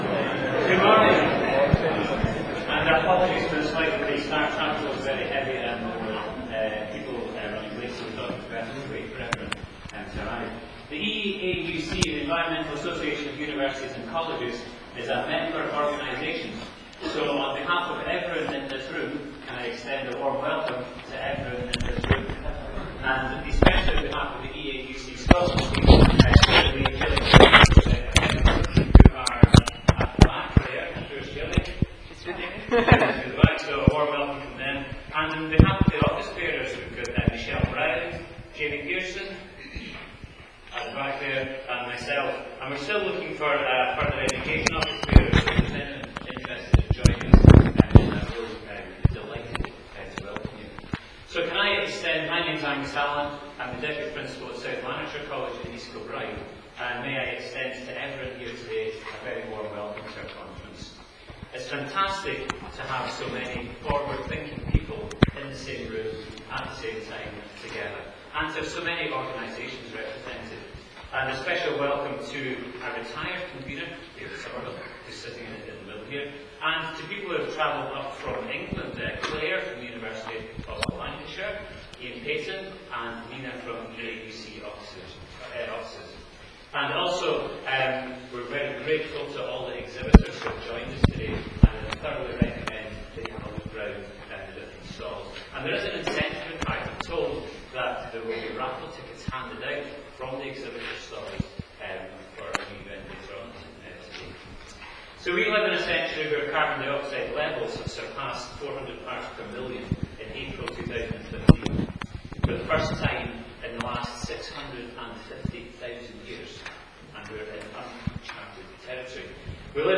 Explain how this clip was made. This audio track includes the two Welcome sessions from the EAUC-Scotland Conference 2015.